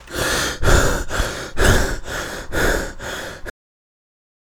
Heavy breath 03
Heavy_breath_03_mp3.mp3